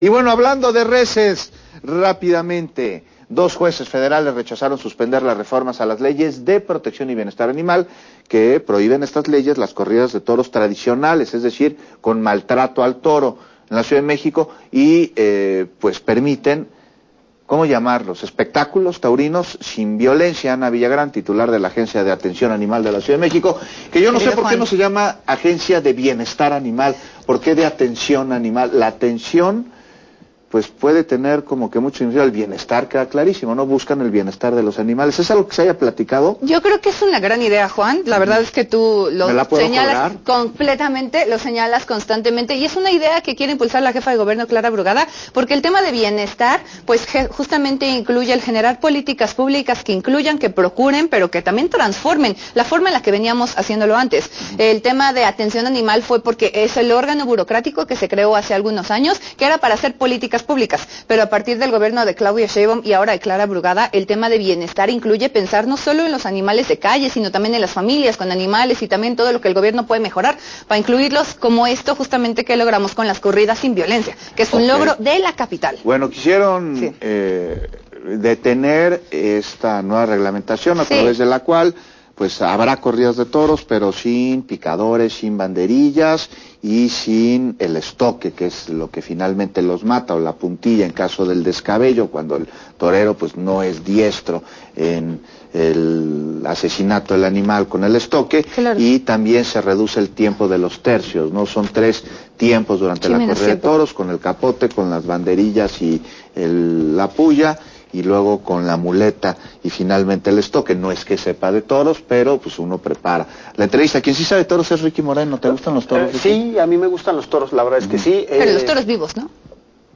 Entrevista a Ana Villagrán: Corridas de Toros